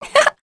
Talisha-Vox-Laugh_2.wav